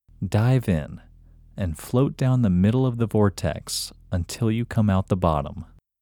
IN – Second Way – English Male 24